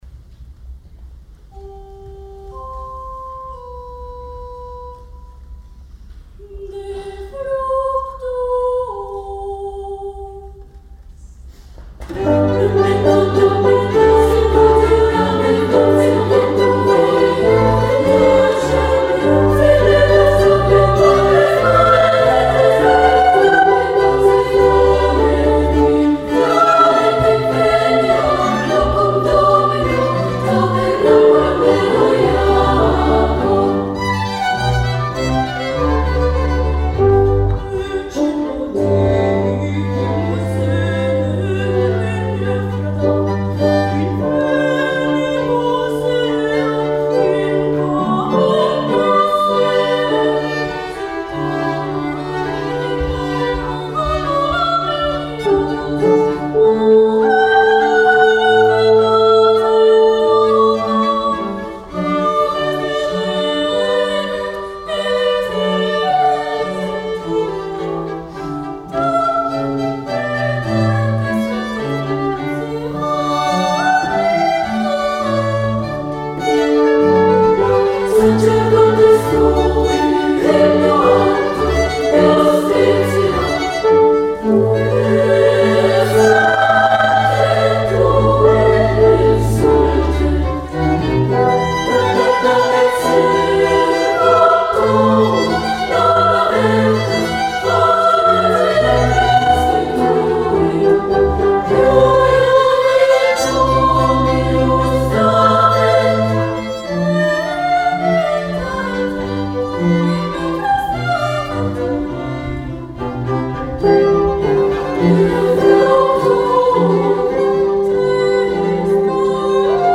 una formació instrumental formada per a un quartet de corda (2 violins, viola i violoncel) i dues trompes
En primer lloc perquè l’obra de Haydn jr em va semblar molt millor que la de Vaňhal, però també perquè tot el protagonisme, malgrat les parts solistes aquí interpretades a la manera vivaldiana, és a dir amb la intervenció estimulant de moltes de les integrants (en aquesta ocasió no hi havia representants masculins), són pel cor. L’obra semblava molt més preparada i cohesionada que l’Stabat Mater de la primera part, i fins i tot la formació orquestral va sonar més conjuntada, afinada i cohesionada amb el cor.
Ambdós concerts, com tots els que d’aquesta temporada, en la millor sala acústica de la ciutat, la del Conservatori del Liceu.
Us deixo escoltar el Memento de les Vesperae pro festo Sancti Innocenti, tal i com va ser interpretat pel Cor Vivaldi en el concert d’ahir.